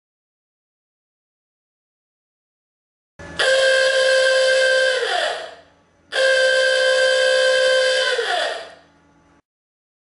AHH-OOO-GAH Horn Chrome 2-Volt With True Sound
AHH-OOO-GAH Horn Chrome  Ah-ooo-Gah Horn with chrome body, vintage Car/Pickup horn. 490 Hz frequency, sound output: 110db (+-)10db*.
Chrome plated, economic style, sounds like Spartan horn.
AHOOOGA-Klaxton-12V-Horn-Loud-and-Proud-HotRod-Classic-sound..mp3